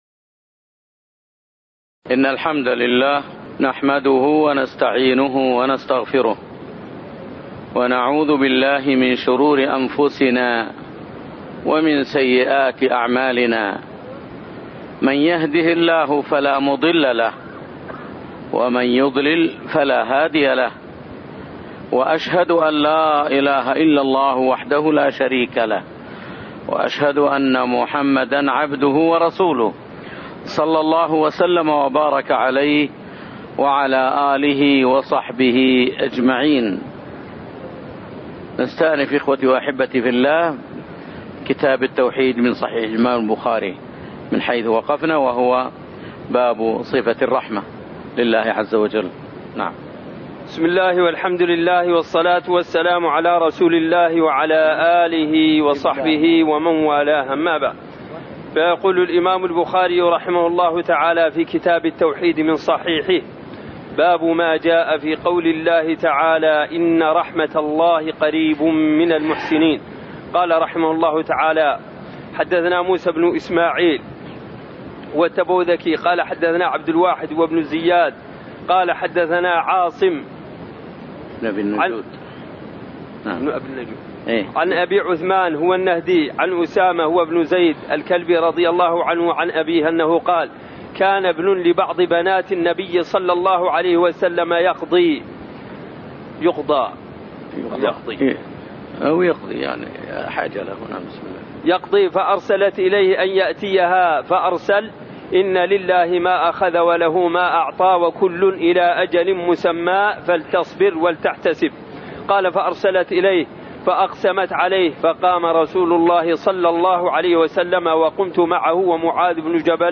تاريخ النشر ٢٤ شوال ١٤٣٤ هـ المكان: المسجد النبوي الشيخ